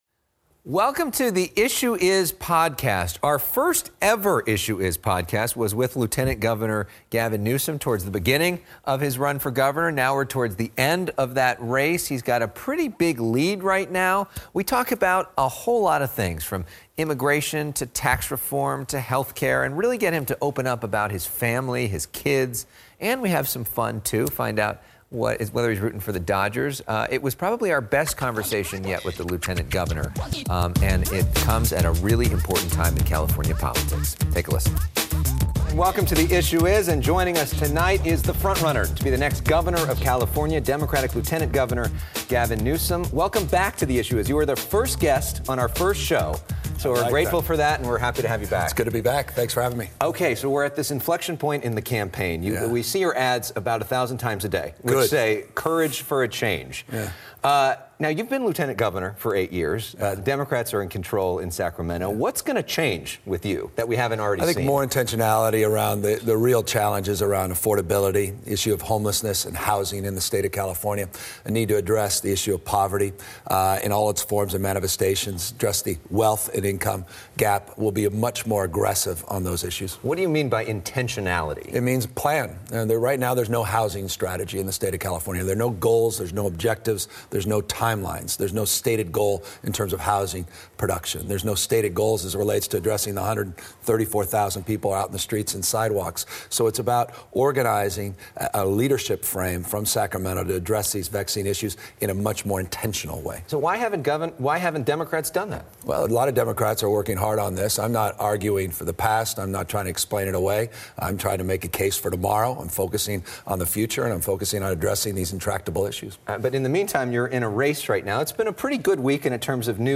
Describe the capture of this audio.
broadcast from FOX 11 Studios in Los Angeles.